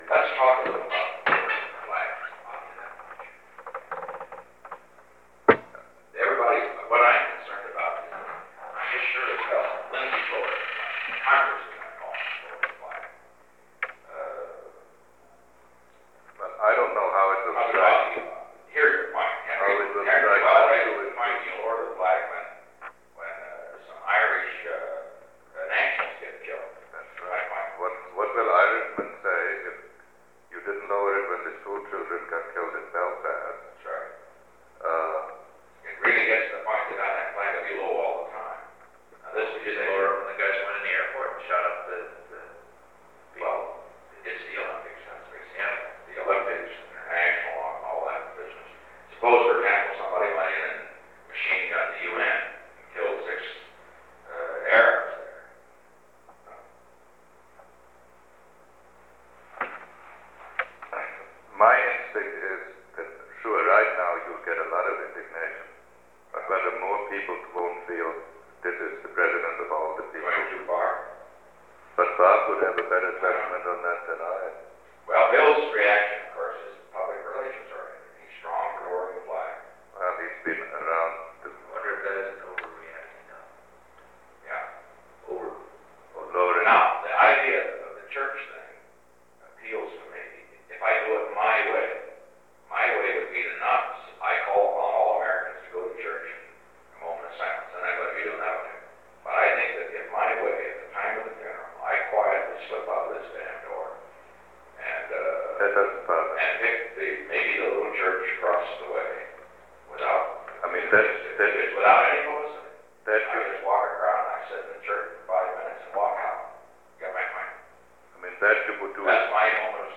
The Presidency / Featured Content 'Keep the Flag Flying' 'Keep the Flag Flying' Photo: The White House In a discussion about lowering the American flag in response to the murder of Israeli athletes at the 1972 Olympic Games, President Nixon, White House Chief of Staff H. R. “Bob” Haldeman, and National Security Adviser Henry A. Kissinger advocated instead for more individual expressions of sympathy. They also examined the President’s standing with American Jews and the virtues of a settlement in the Middle East. Date: September 6, 1972 Location: Oval Office Tape Number: 771-005 Participants Richard M. Nixon Henry A. Kissinger H. R. “Bob” Haldeman Associated Resources Audio File Transcript